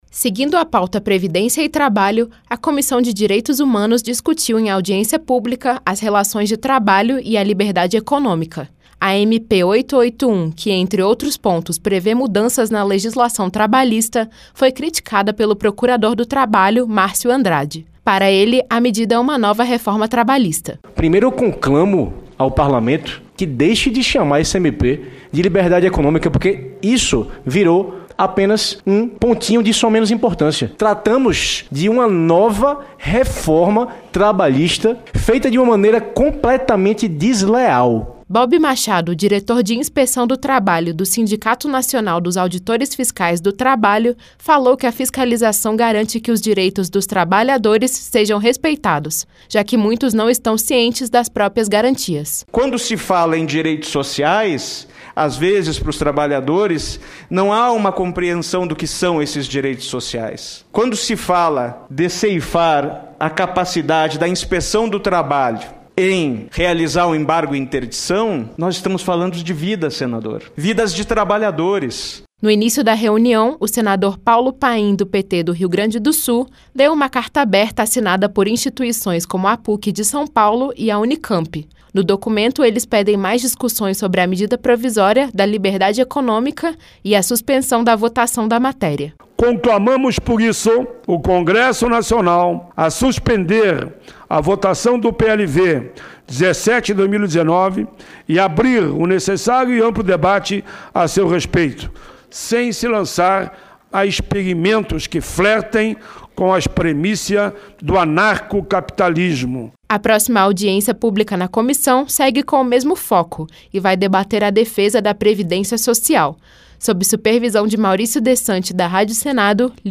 A Comissão de Diretos Humanos (CDH) promoveu mais uma audiência pública nesta terça-feira (13) para discutir o tema relações de trabalho e liberdade econômica.